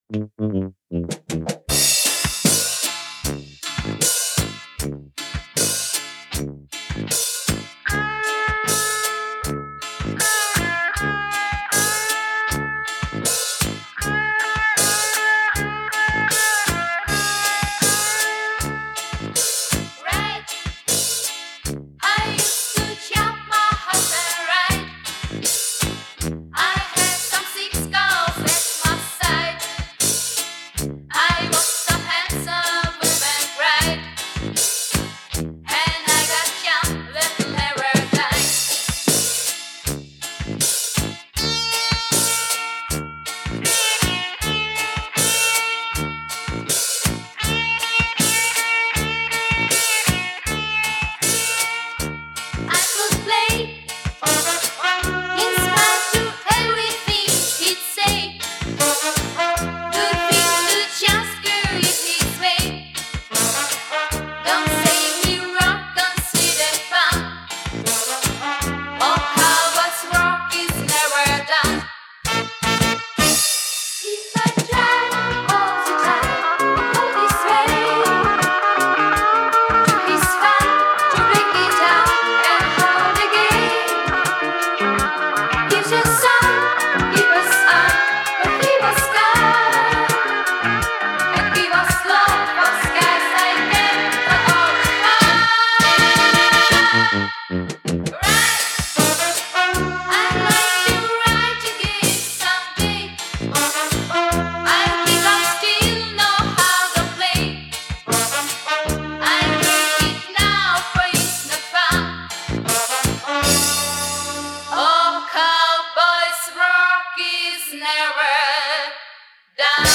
Жанр: Rock, Pop
Формат: 2LP In 1CD, Stereo, Album, Remastered, Reissue
Стиль: Vocal, Pop Rock, Schlager